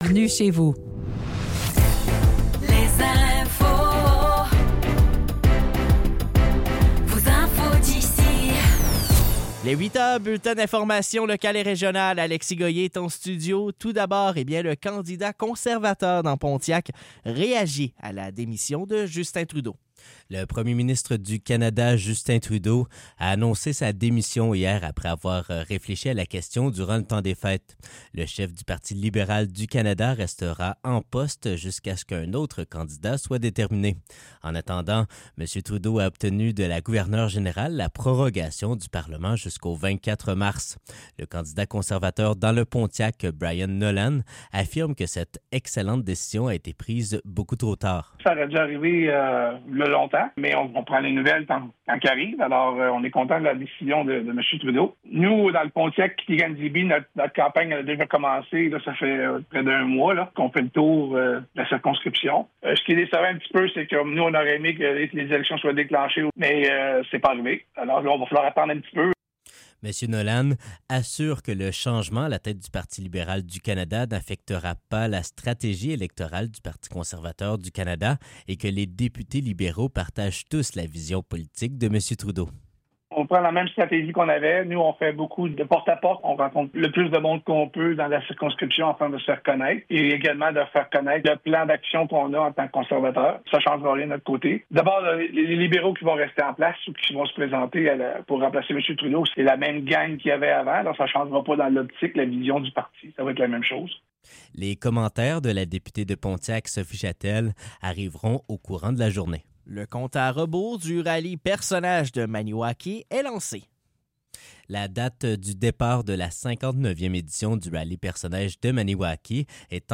Nouvelles locales - 7 janvier 2025 - 8 h